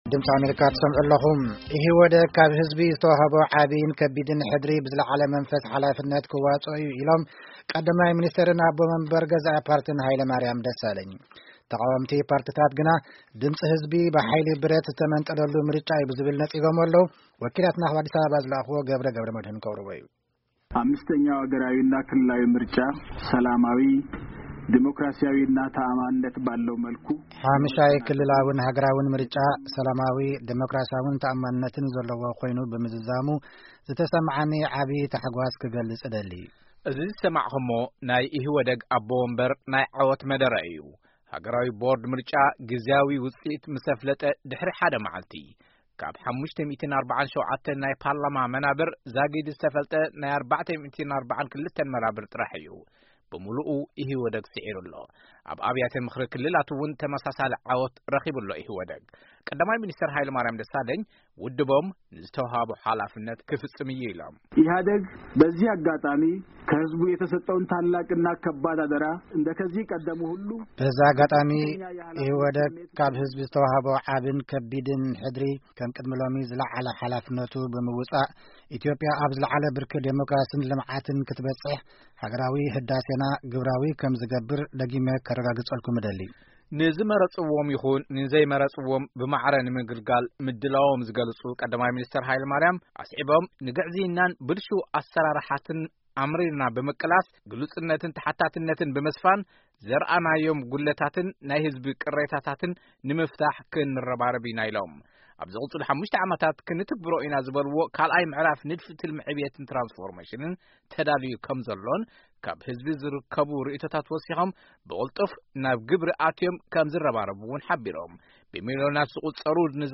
መደረ ቀ/ሚ ሃይለማሪያምን መልሲ ተቓወምትን